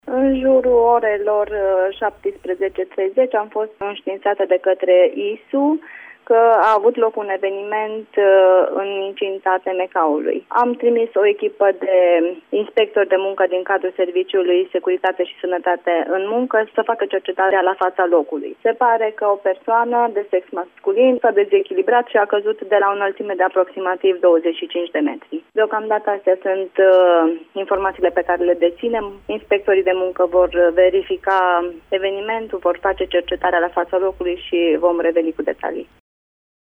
Informaţii despre producerea accidentului am aflat de la inspectorul şef al ITM Caraş-Severin, Eugenia Mihăescu: